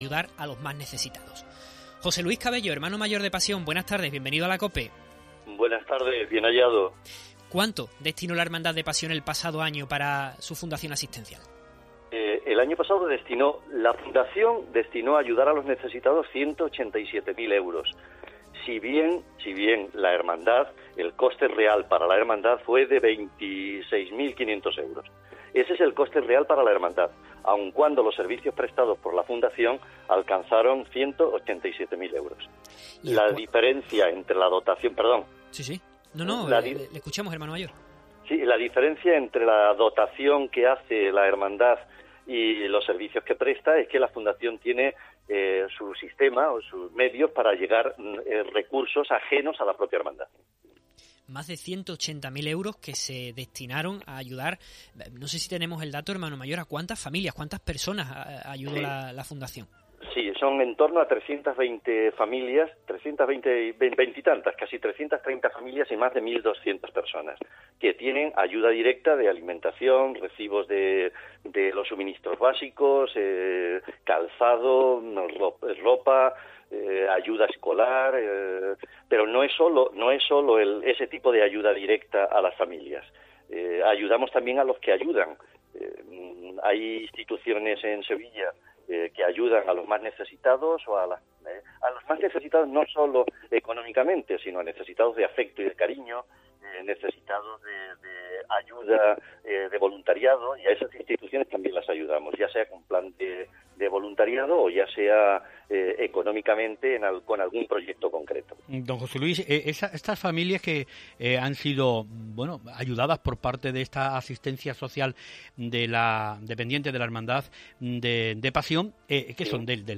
Entrevista a nuestro Hermano Mayor sobre la Fundación Asistencial